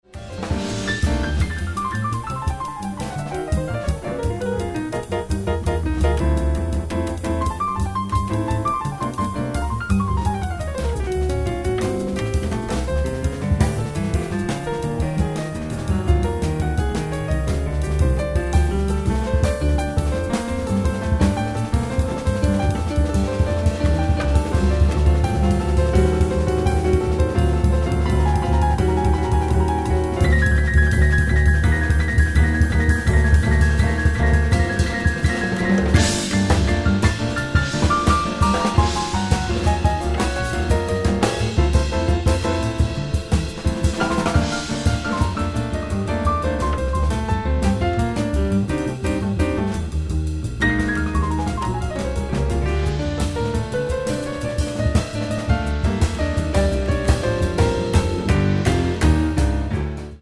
drums
acoustic bass